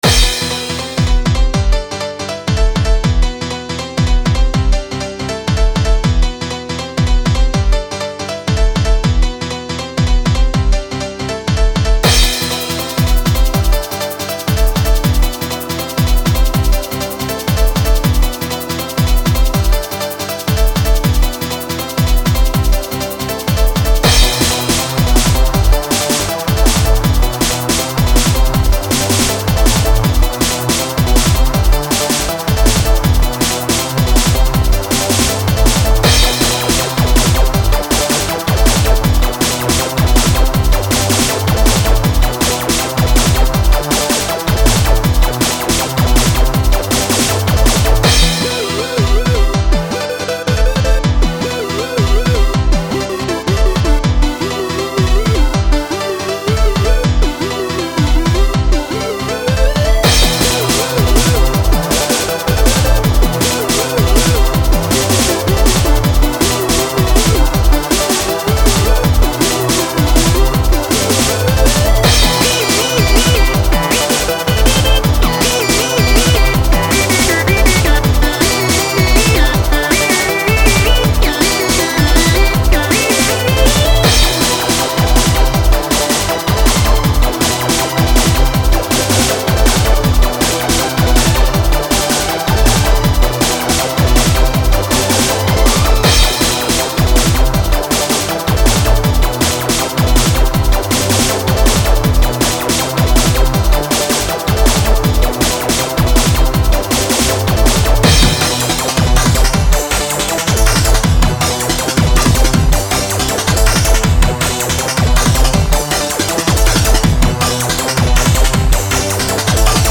especially the drumloop and the final drop
this song was inspired by old 90s breakbeat music
the only thing that isn't too "90s-ish" is the string and the reverb-y bells at the end
160 bpm
breakbeat break oldschool old_school 90s